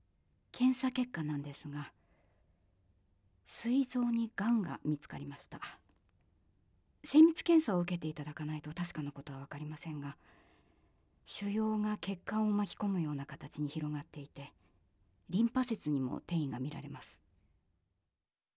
セリフA
ボイスサンプル